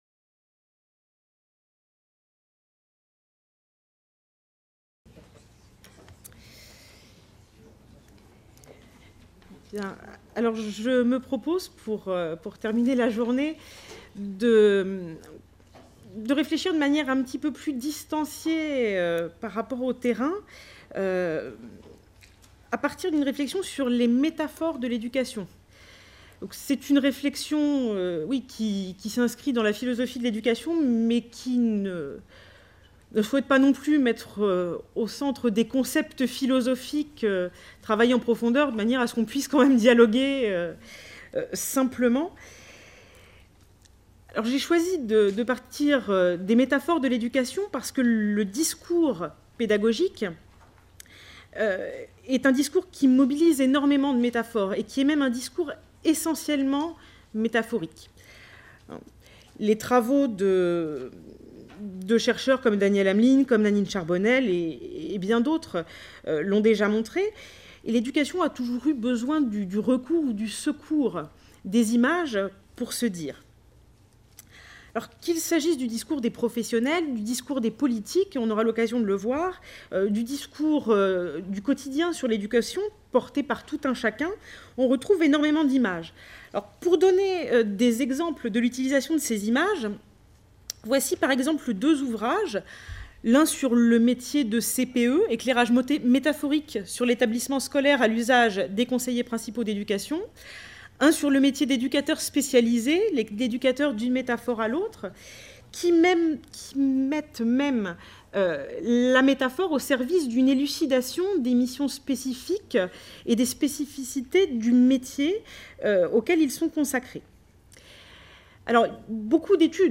Journée d’étude organisée par l’équipe Enfances, Jeunesses et Cultures (EJC), CERSE EA965 (Centre d’Etudes et de Recherche en Sciences de l’Education) Coopérations entre adultes et réussite des enfants : quels processus, quels effets ?